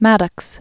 (mădəks), Gregory Alan Known as "Greg."